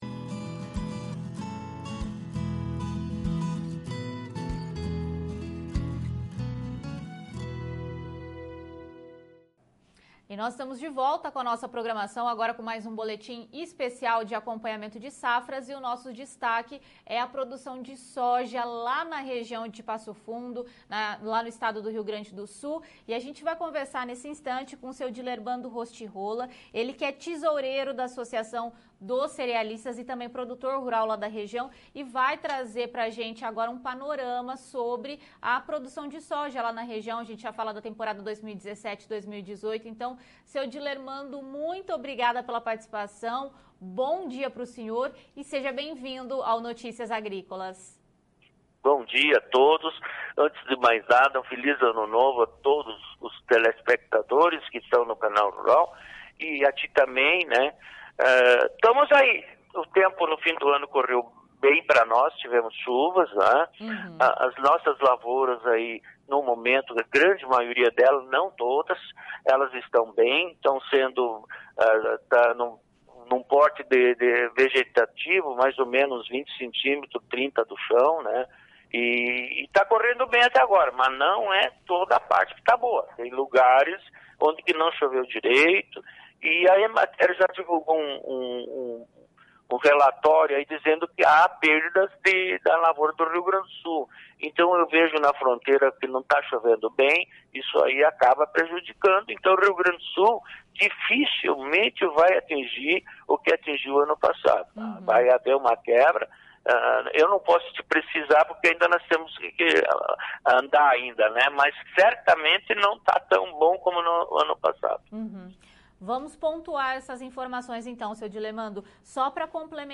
Produtor Rural